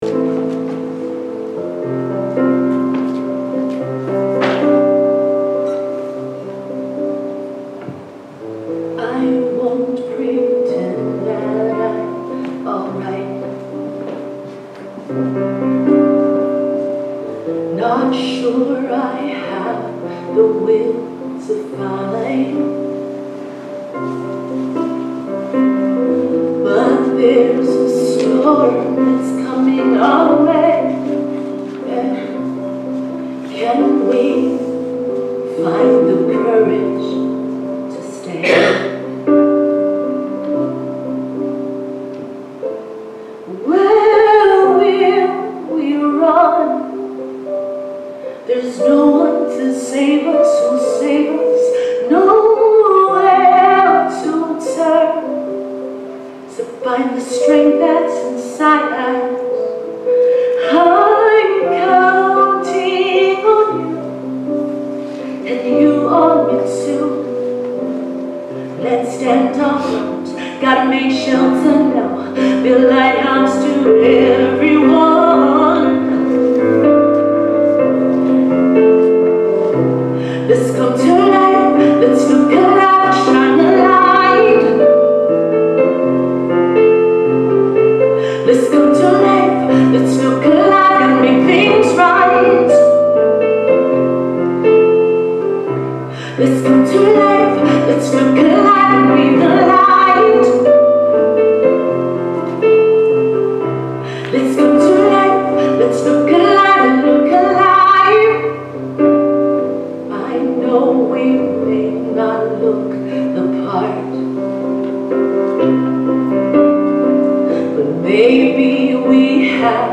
Spiritual Leader Series: Sermons 2023 Date